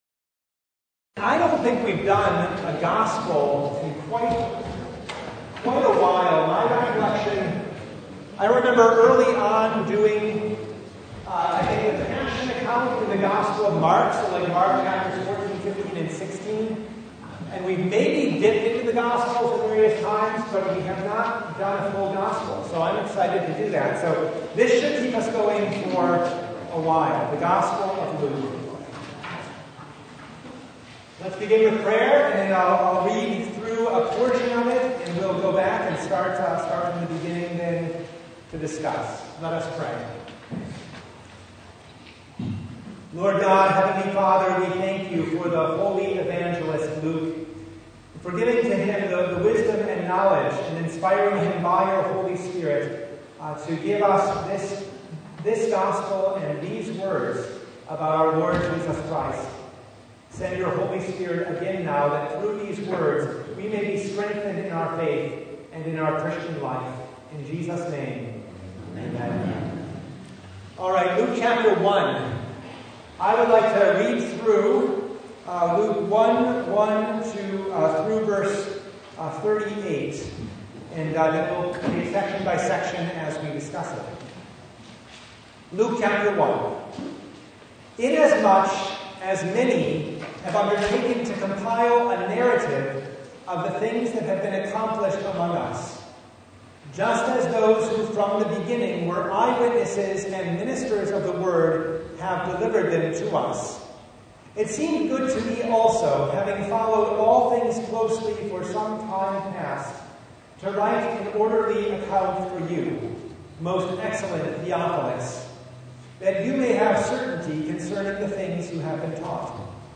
Luke 1 1:25 Service Type: Bible Study Topics